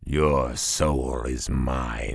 Hero Lines